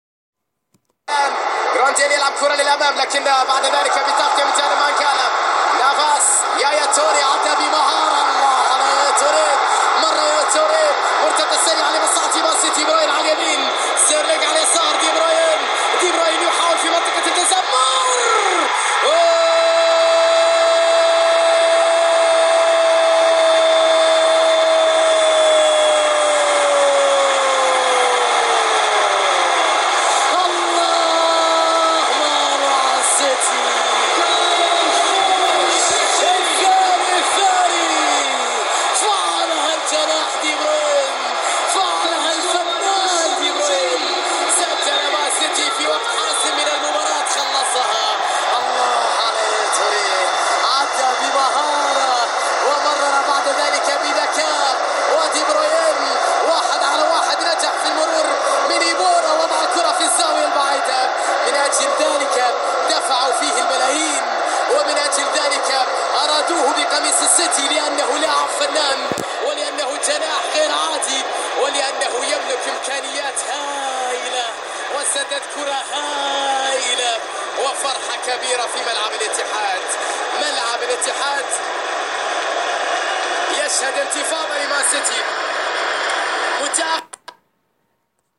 گزارش جنون آمیز فهد العتیبی بعد از گلزنی دیبروین